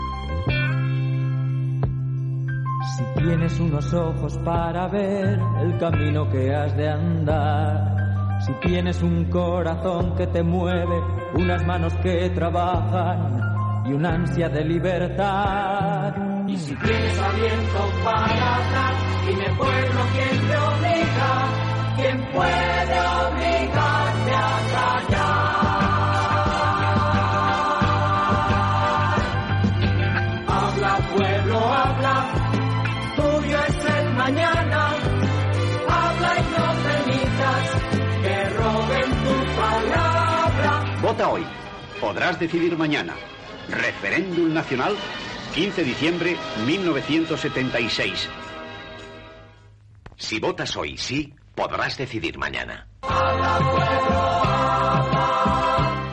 Propaganda de la celebració del Referèmdum Nacional per aprovar la Llei per a la Reforma Política